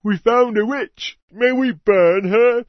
PeasantPissed2.mp3